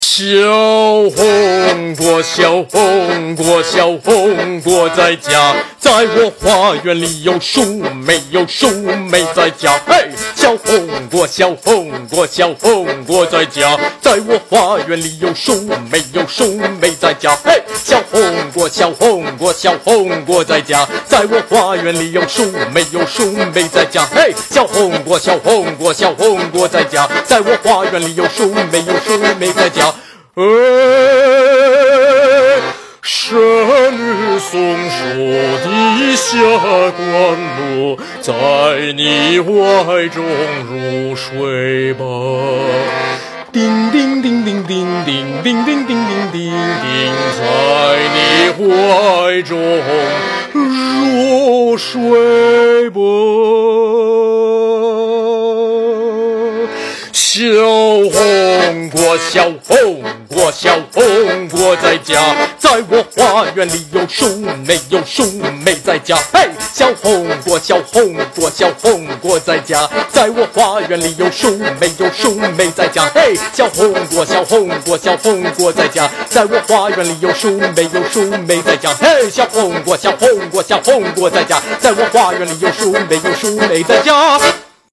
Russian folk song
Performed in Chinese on accordion.